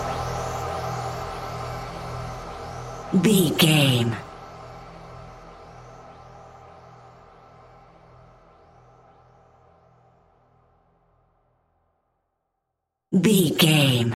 Sound Effects
Atonal
tension
ominous
dark
eerie
horror